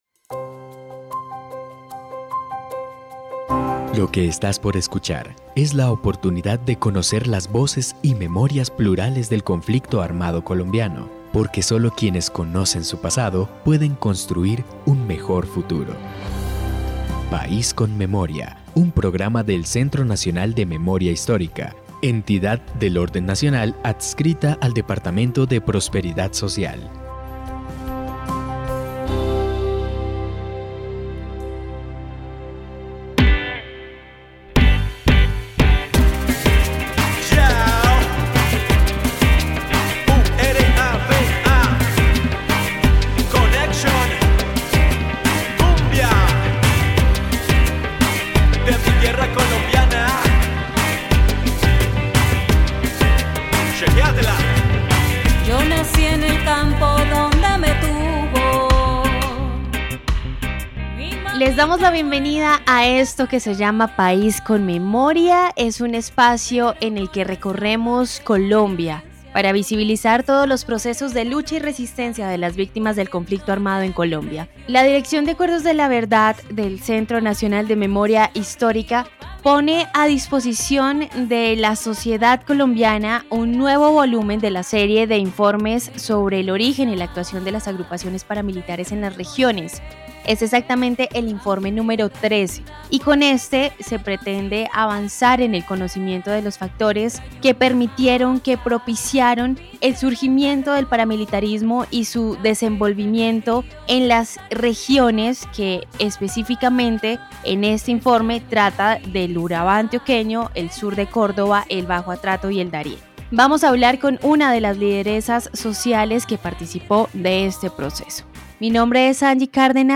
Conversación con uno de los líderes sociales que ha participado de estos procesos educativos para la construcción de memoria y paz.